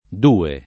d2e] num. — tronc. (fam. tosc.) in procl.: là ’n sulle du’ ore di notte [